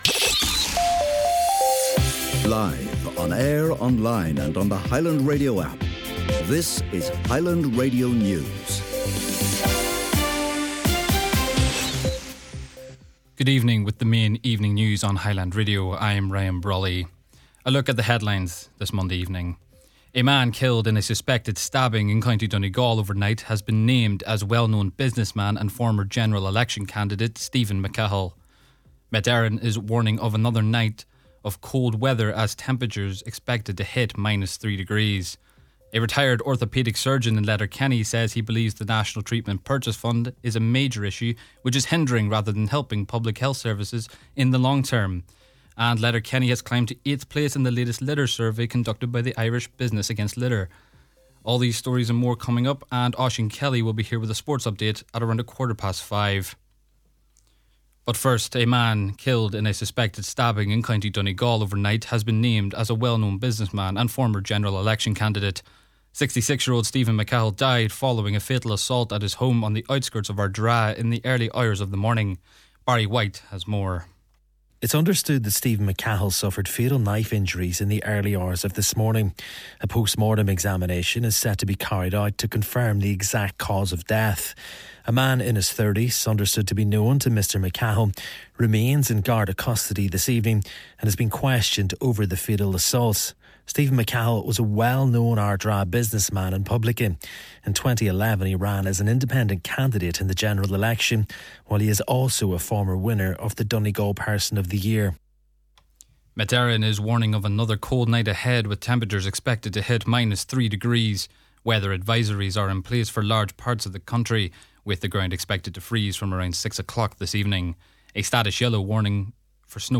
Main Evening News, Sport & Obituary Notices – Monday January 5th